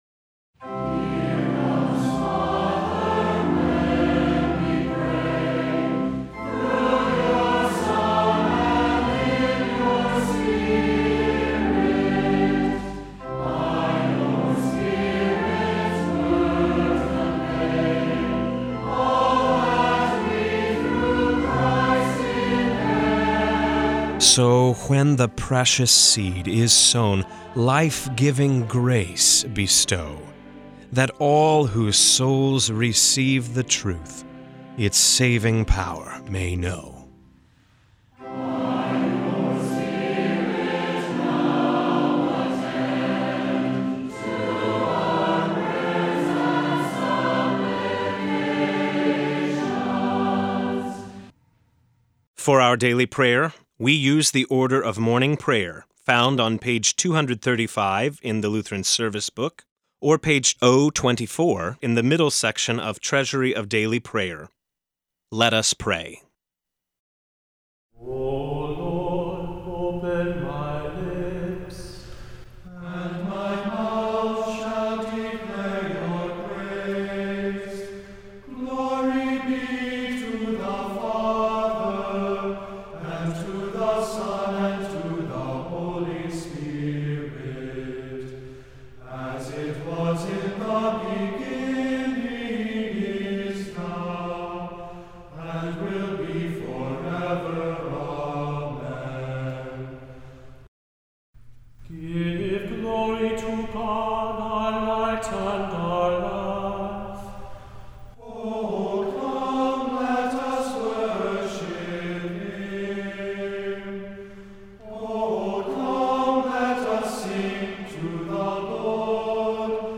This is a rebroadcast from March 8, 2017. Hear a guest pastor give a short sermonette based on the day’s Daily Lectionary New Testament text during Morning and Evening Pr